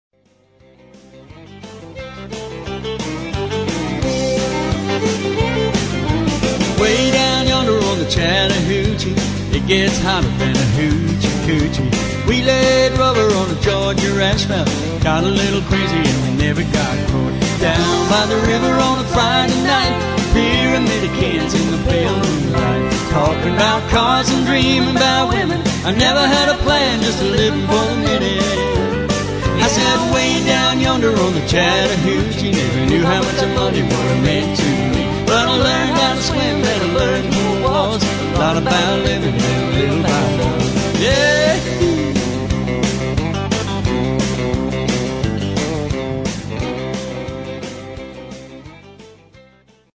Solo Entertainer Country - Rock N Roll - Classics